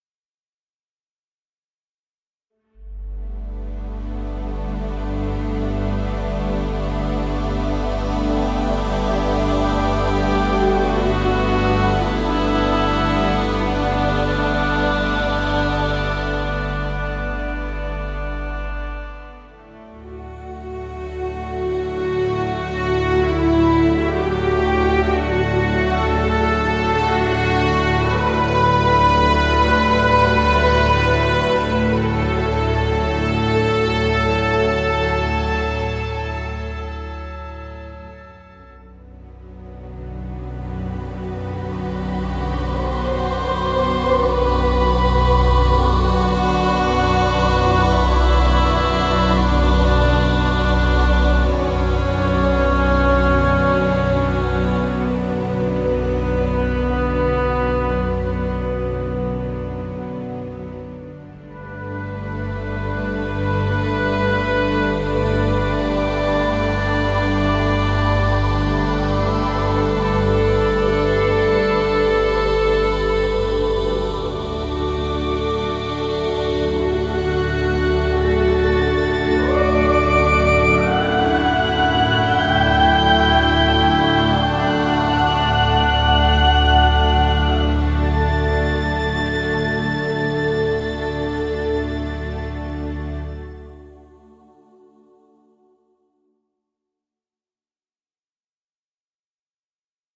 它包括持续音，断奏音和富有声音设计的合唱氛围和垫音，包裹在一个直观的界面中。
这个音色库是在一个大厅里录制的，拥有明亮，清晰和自然绝佳的音色。它是由一个 25 人的男童合唱团录制的，由获奖的 Pacific Boychoir 指挥。
合唱效果部分包括无伴奏和低语发声，颤音，扫弦，涨落，上升，下降和其他合唱效果。你还可以找到两个精美的独唱者（年轻的女高音和年长的女中音），带有多音节短语，断奏和真实连奏持续音。